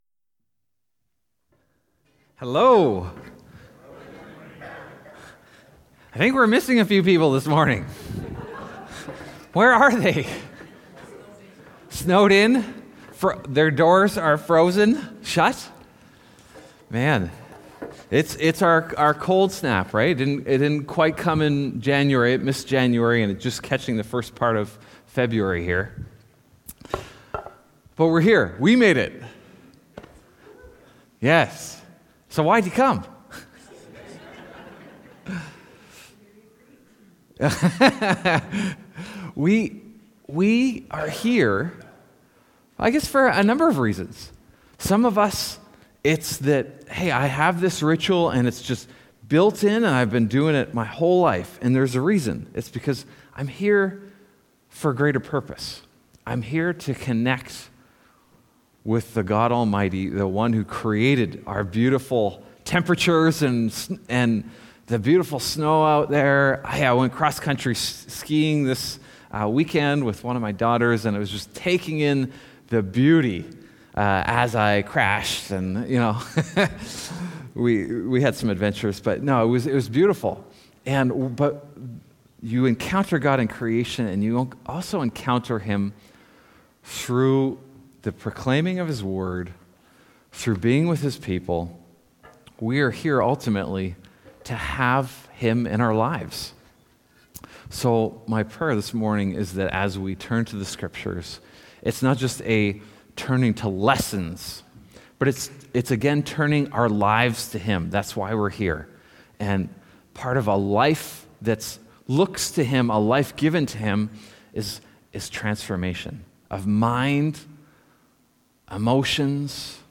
Sermons | Northstar Church